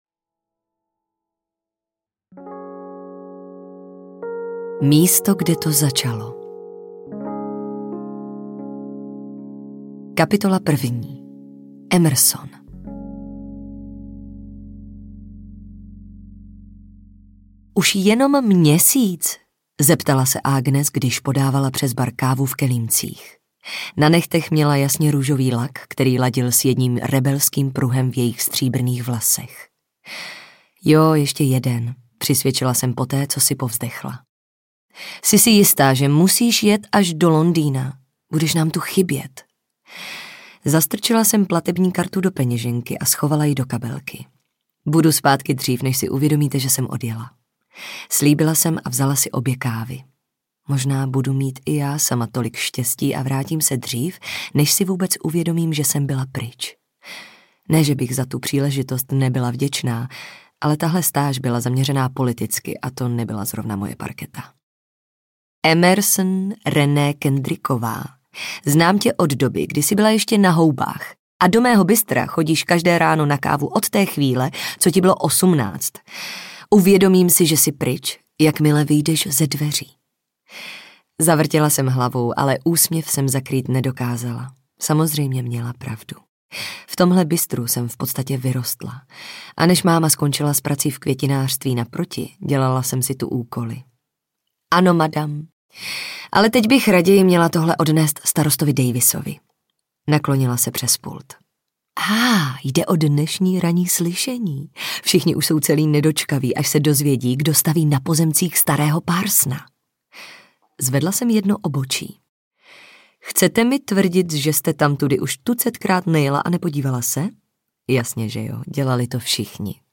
Druhá šance audiokniha
Ukázka z knihy